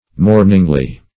mourningly - definition of mourningly - synonyms, pronunciation, spelling from Free Dictionary Search Result for " mourningly" : The Collaborative International Dictionary of English v.0.48: Mourningly \Mourn"ing*ly\, adv.